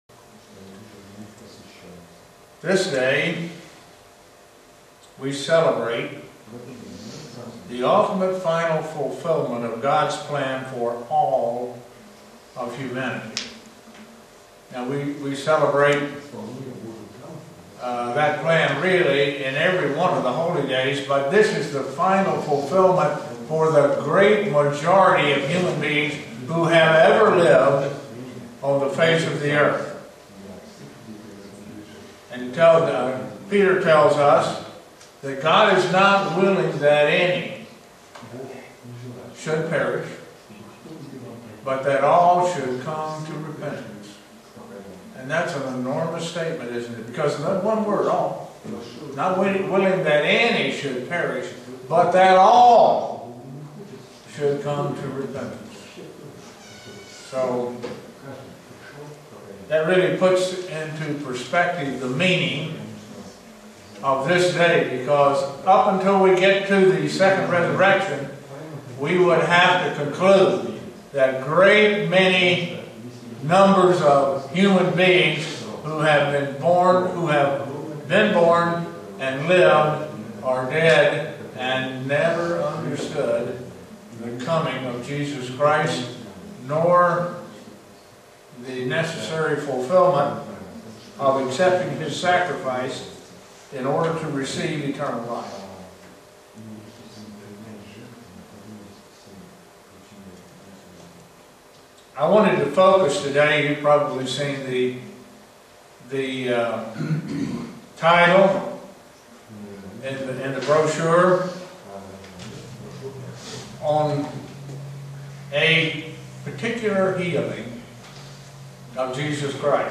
Print A healing by Christ done on a Sabbagh that can relate to the Last Great Day also known as the Eighth Day. sermon Studying the bible?